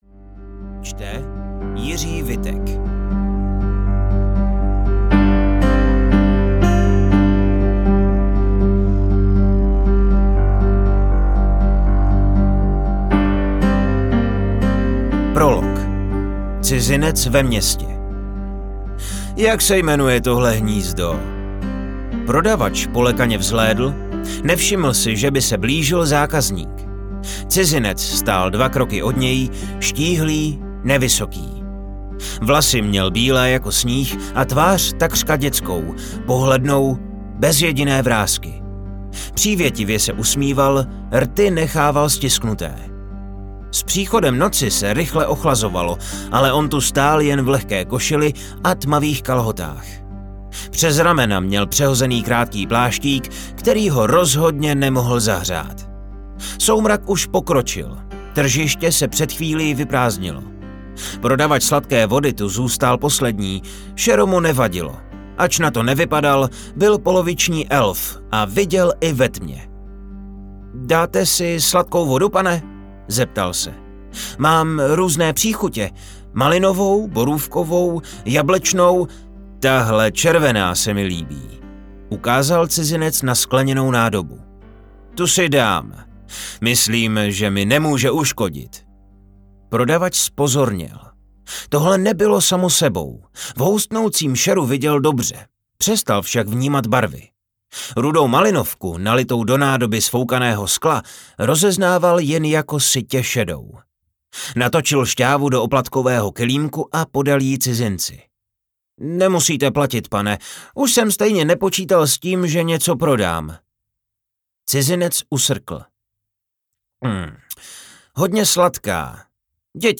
Likario audiokniha
Ukázka z knihy